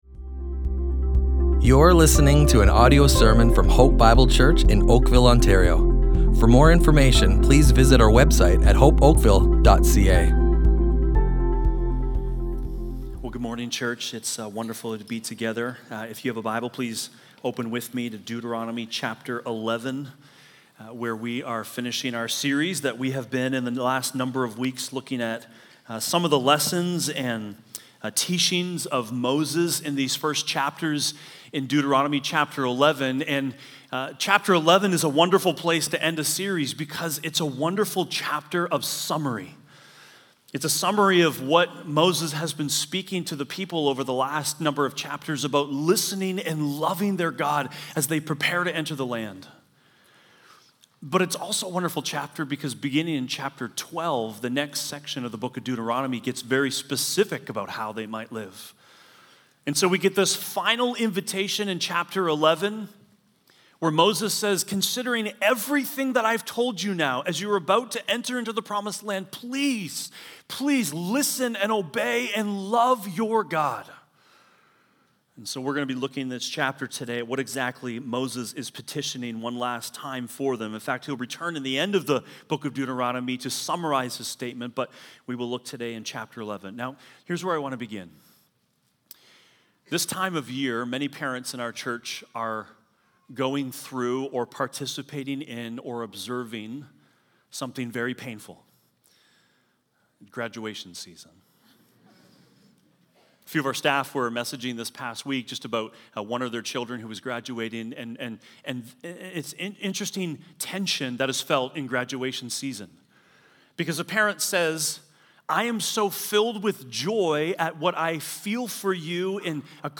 Hope Bible Church Oakville Audio Sermons Listen and Love // So What Will You Choose?